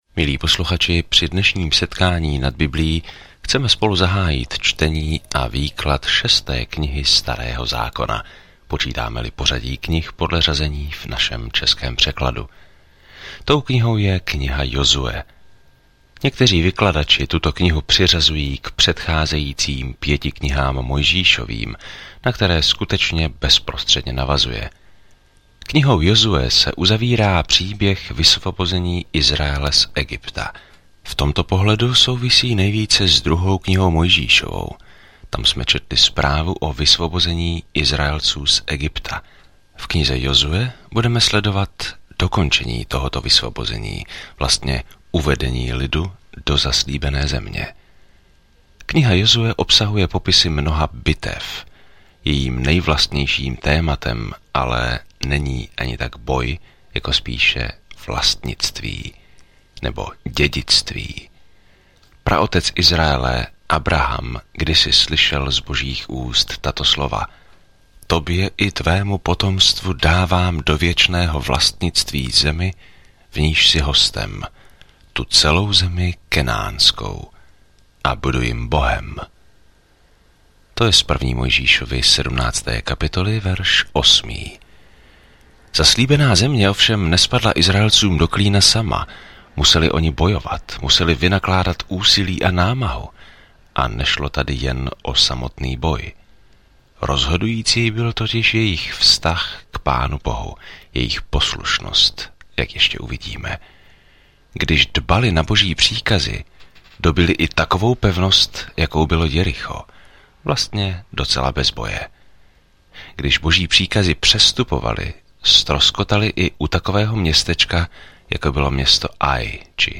Písmo Jozue 1 Jozue 2:1 Začít tento plán Den 2 O tomto plánu Nazvěme knihu Jozue „Exodus: Část druhá“, když nová generace Božího lidu zabírá zemi, kterou jim slíbil. Denně procházejte Jozuem, zatímco posloucháte audiostudii a čtete vybrané verše z Božího slova.